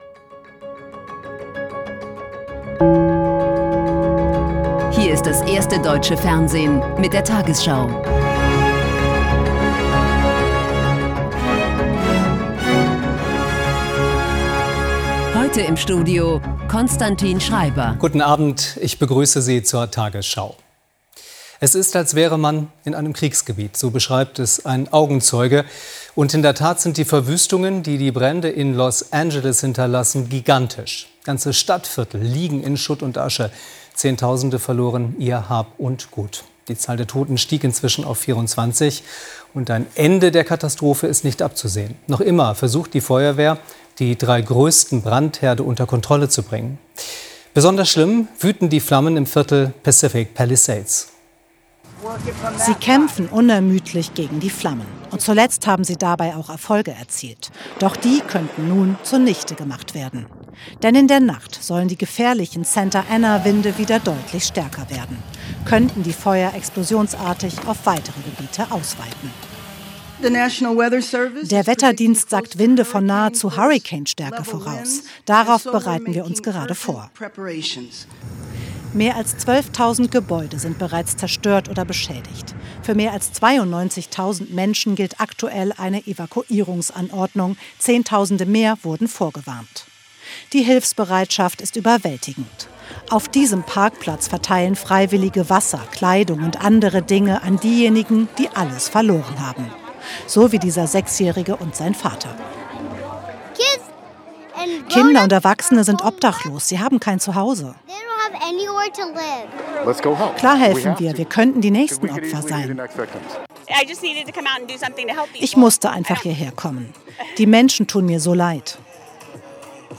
Deutschlands erfolgreichste Nachrichtensendung als Audio-Podcast.